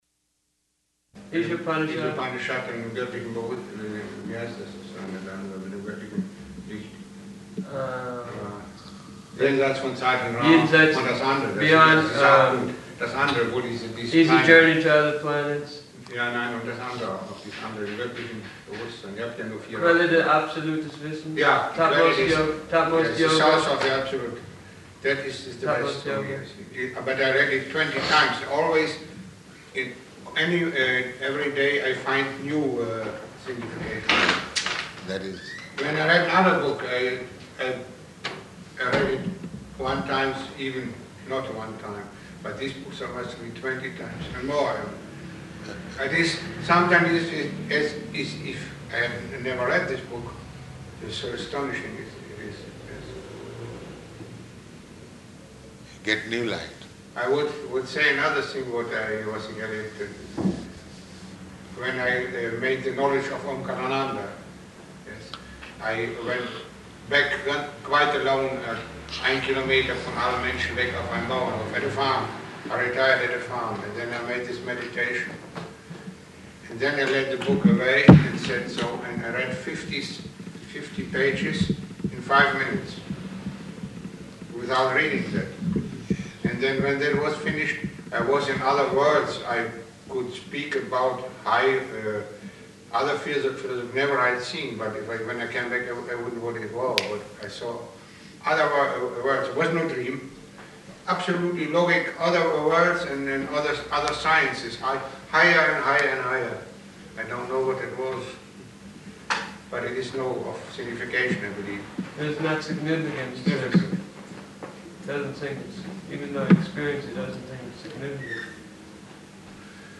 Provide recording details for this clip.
-- Type: Conversation Dated: August 9th 1973 Location: Paris Audio file